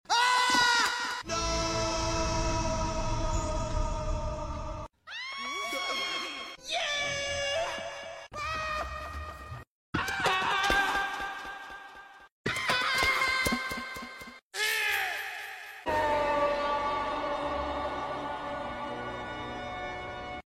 Super Smash Bros. Toy Story Star KO Sounds sound effects free download